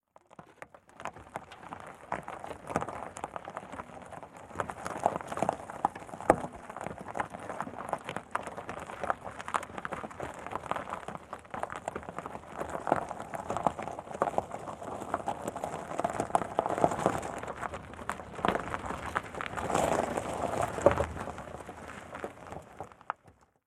Звук колобка медленно катящегося